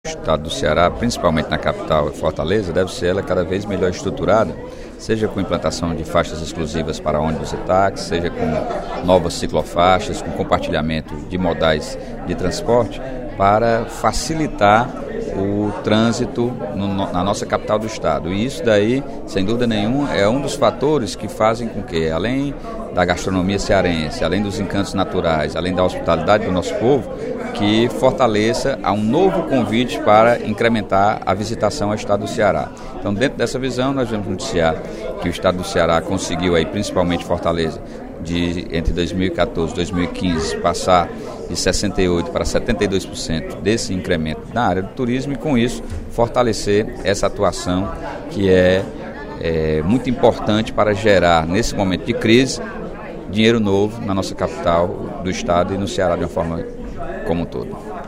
O deputado Sérgio Aguiar (PDT) divulgou, no primeiro expediente da sessão plenária desta quinta-feira (31/03), números do Ministério do Turismo sobre o lucro do setor no Ceará, em 2015.